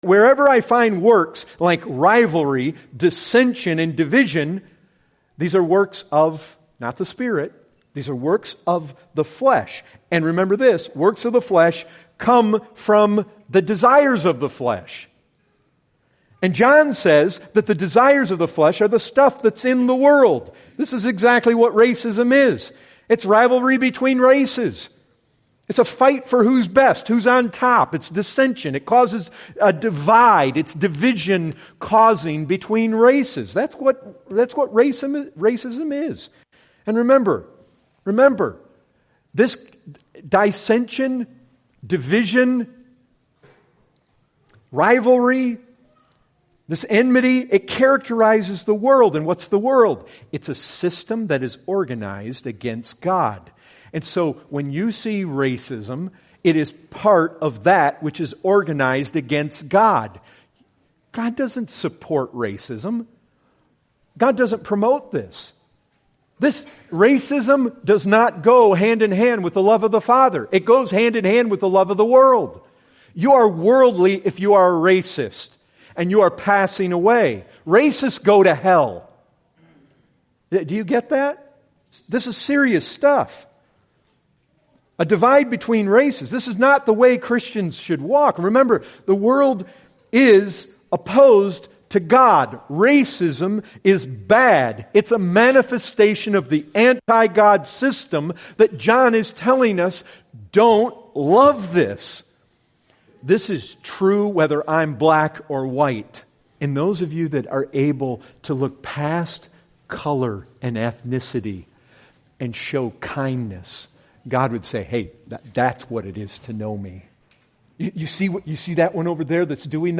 Excerpts taken from the full sermon, “Racism Condemned” preached on 12/21/2014.